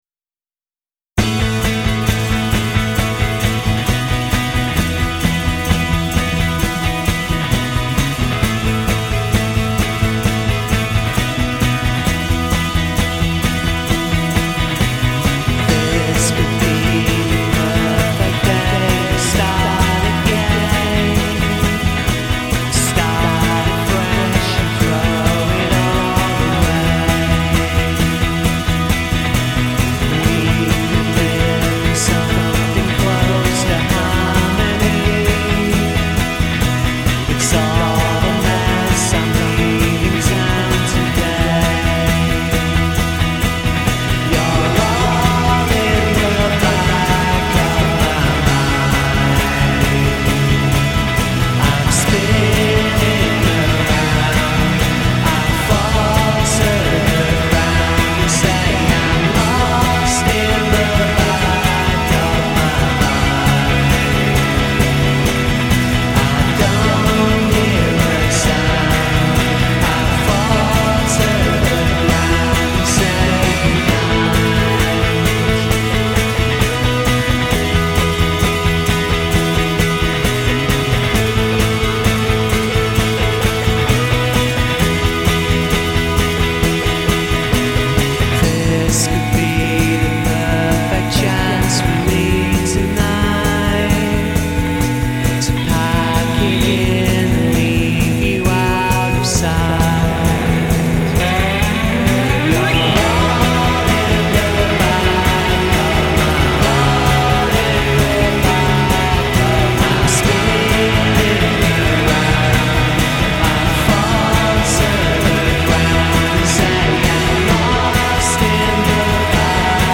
It has a trippy vocal echo over a lively guitar jangle.